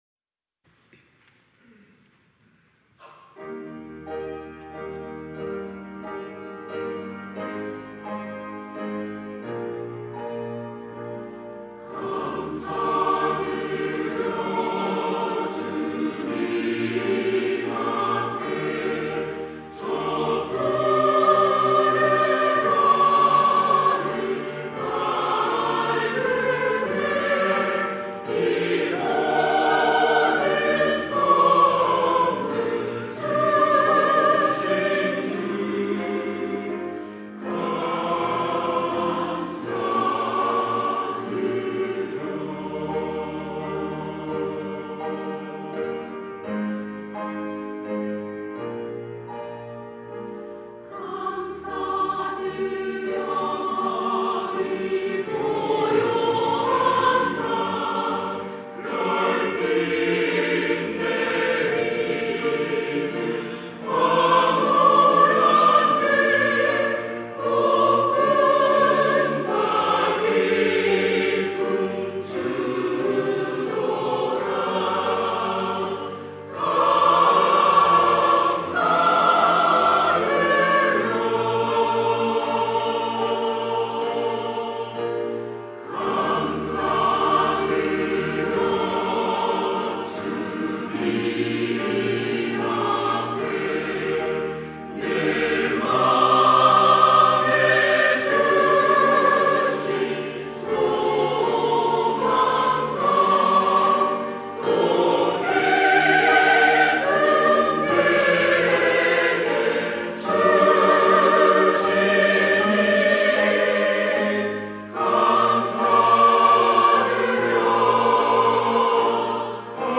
시온(주일1부) - 감사드려
찬양대